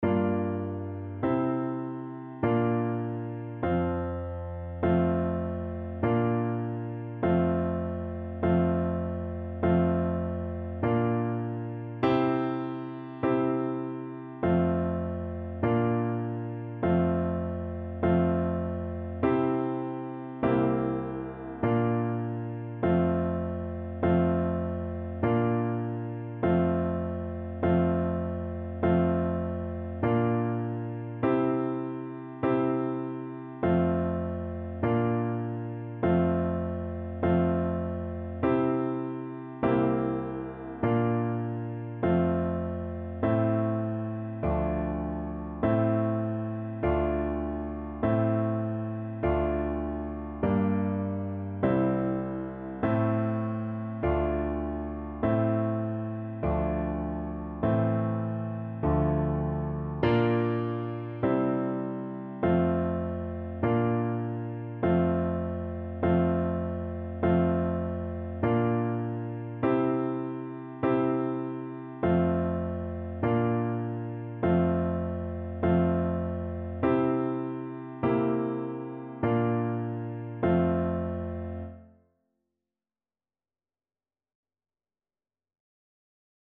4/4 (View more 4/4 Music)
F major (Sounding Pitch) (View more F major Music for Tuba )